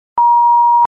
Censor Beep Sound Effect